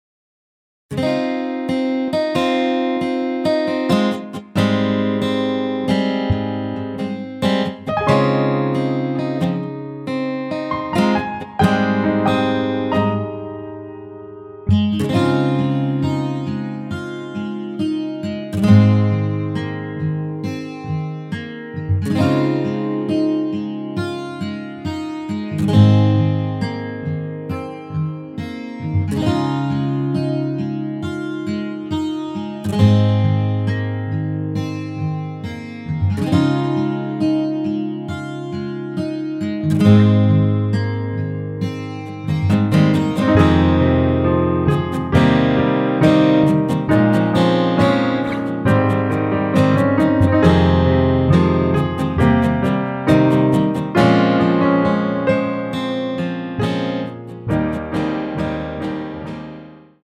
원키에서(-3)내린 MR입니다.
Ab
앞부분30초, 뒷부분30초씩 편집해서 올려 드리고 있습니다.
중간에 음이 끈어지고 다시 나오는 이유는